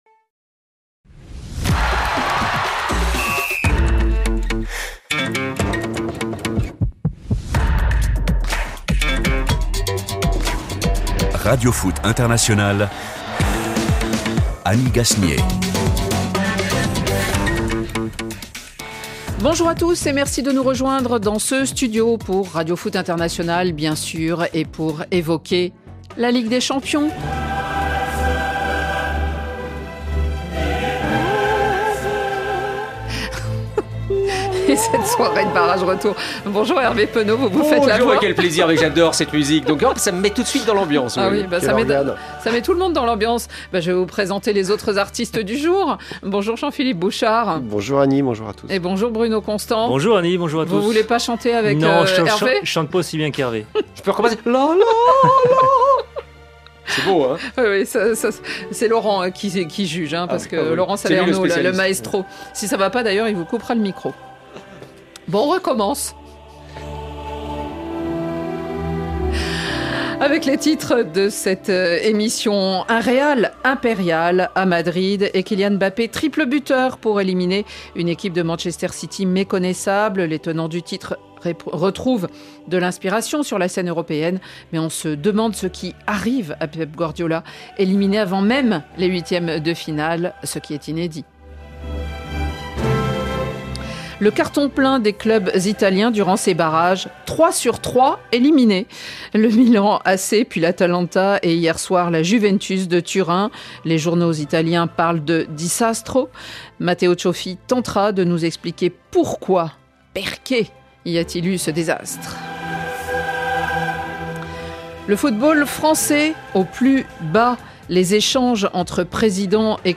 1 (Rediff) Les symboles de la Seconde Guerre mondiale, de la Résistance à l'Epuration 46:04 Play Pause 1h ago 46:04 Play Pause Lire Plus Tard Lire Plus Tard Des listes J'aime Aimé 46:04 Découvrez l’Histoire de France et du monde avec l’historienne Virginie Girod dans cette nouvelle saison du podcast "Au cœur de l’Histoire" ! Embarquez pour un voyage dans le temps inédit sur fond de musiques originales, pour une immersion totale à la manière de la fiction audio.